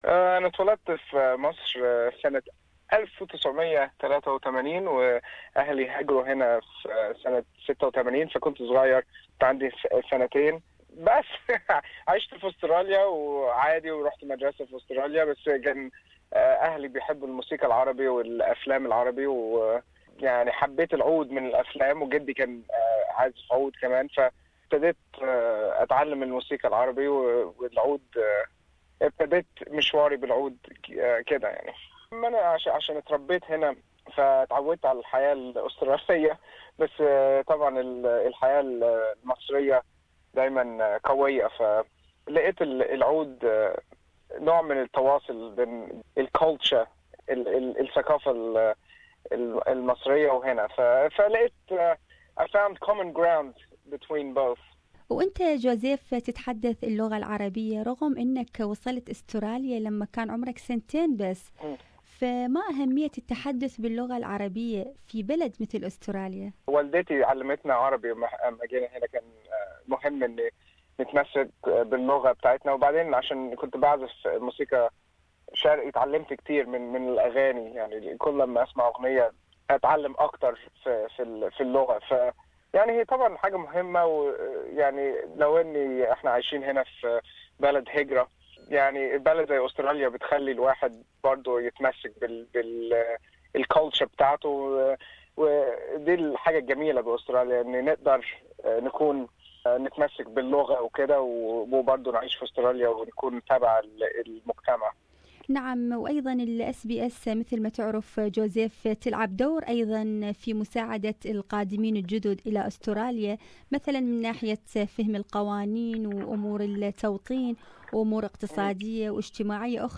The Australian Egyptian Oud player Joseph Tawadros congratulates SBS Radio on the occasion of the launch of SBS Arabic digital radio 24 in an interview